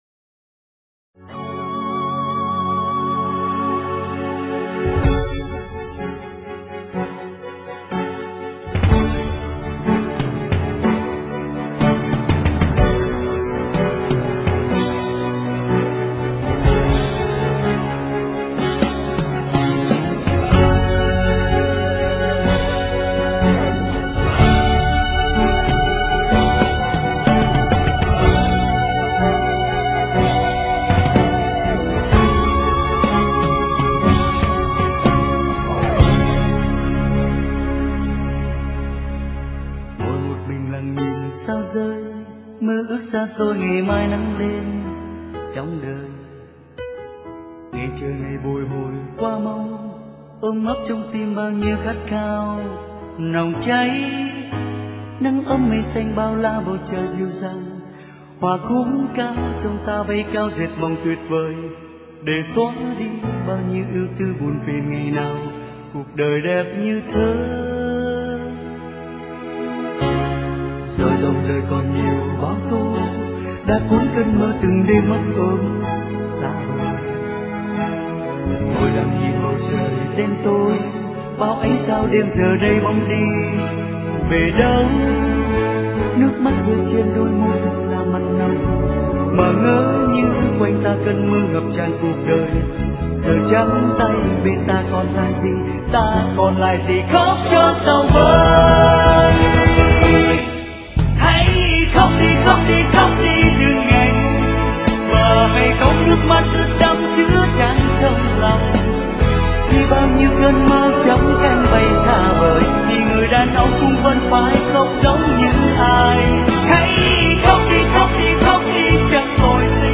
* Thể loại: Nhạc Việt